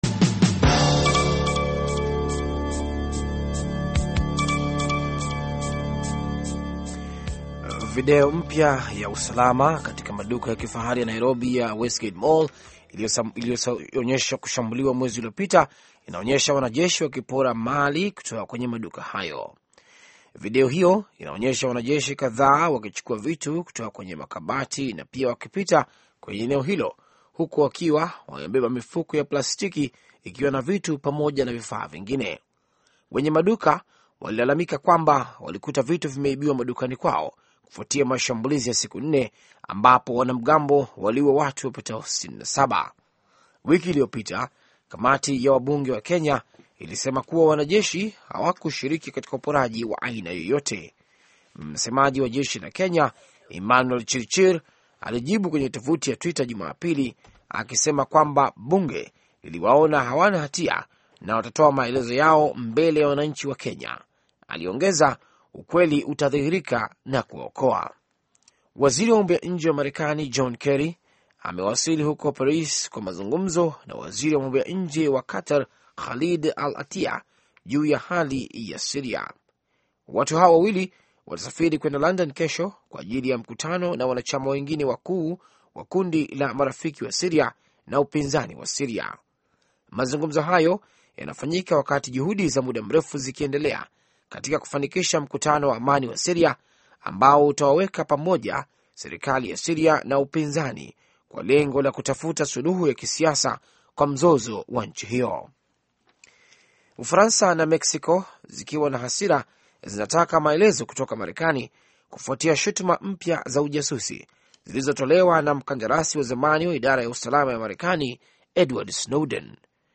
Taarifa ya Habari VOA Swahili - 6:50